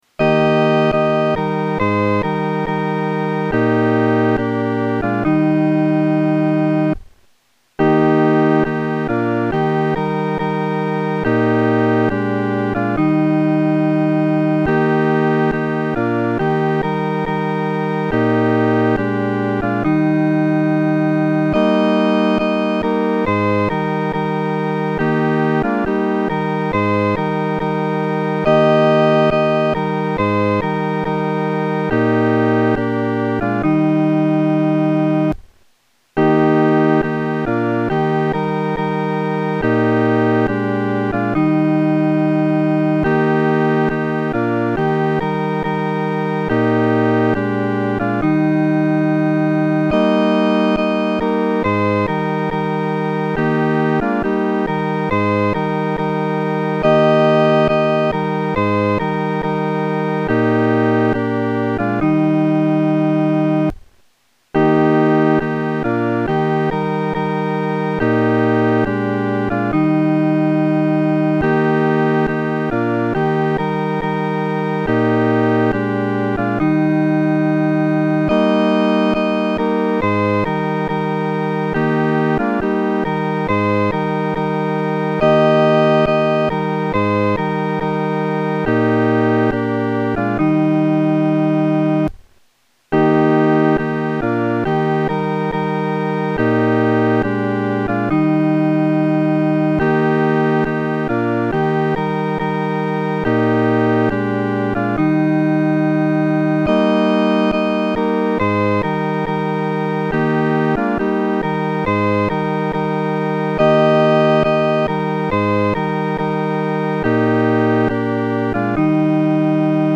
伴奏
四声
本首圣诗由网上圣诗班 (青草地）录制